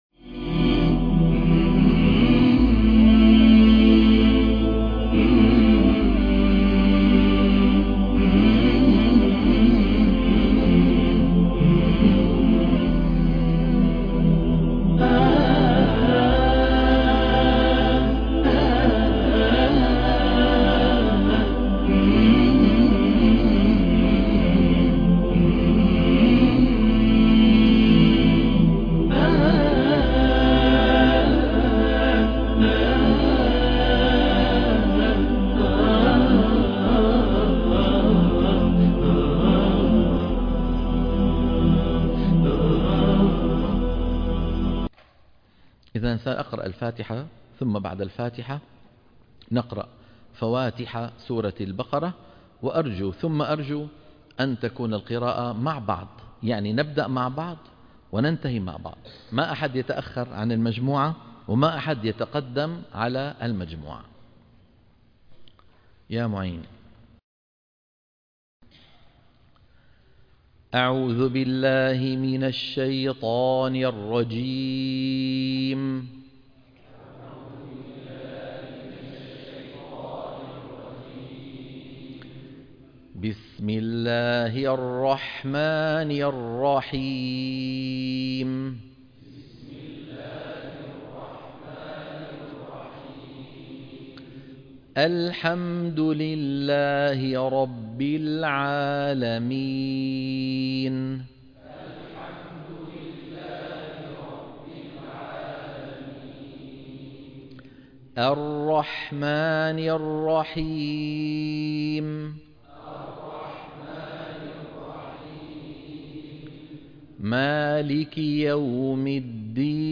تصحيح التلاوة الحلقة - 2 - تلقين سورة الفاتحة وفواتح البقرة - الشيخ أيمن سويد